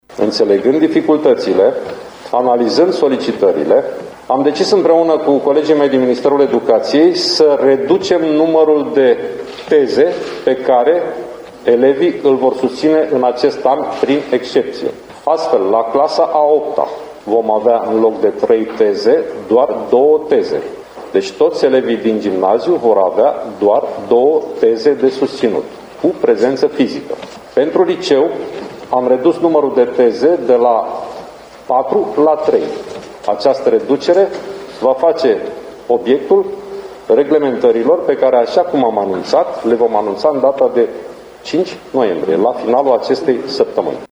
Câmpeanu a precizat că tezele vor fi susținute cu prezență fizică, începând cu data de 6 decembrie: